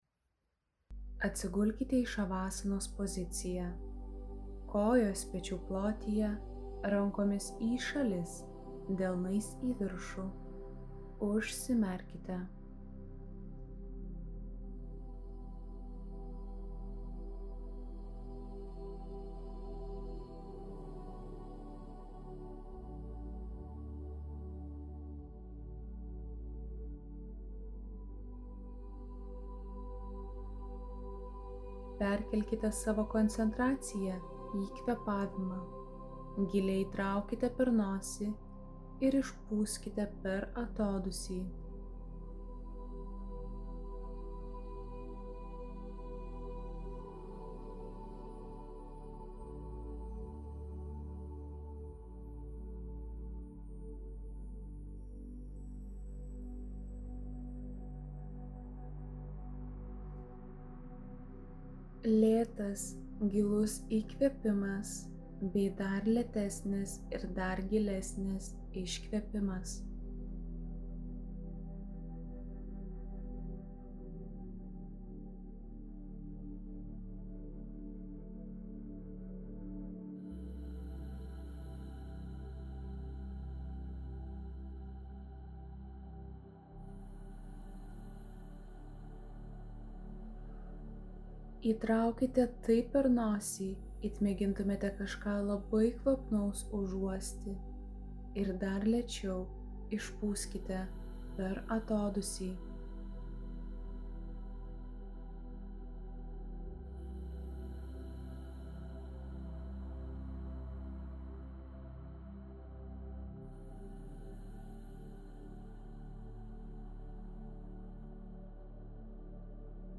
Ši kvėpavimo meditacija skirta atkurti ryšį su tėčiu (gimdytoju).
kvepavimo-meditacija-tetis-d95yQwjN7gc2xVDl.mp3